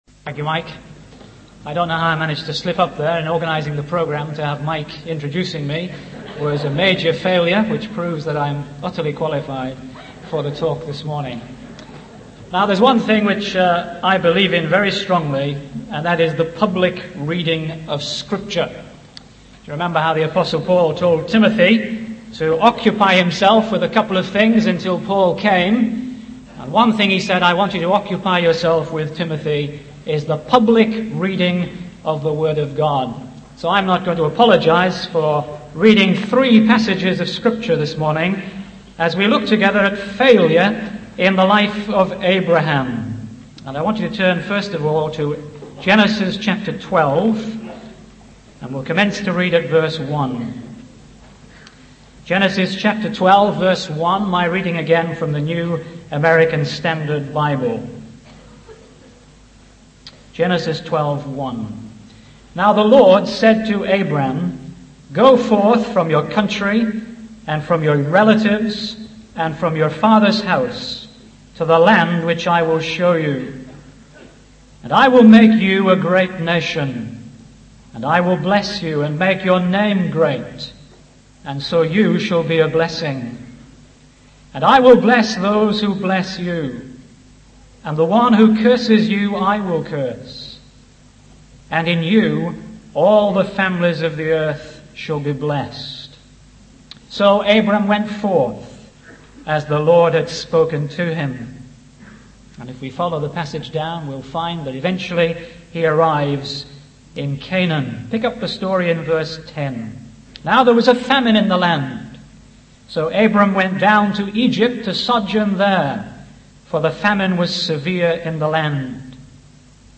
In this sermon, the preacher focuses on the story of Abraham and how his faith was tested. The preacher highlights how Abraham's faith faltered when faced with pressure and uncertainty.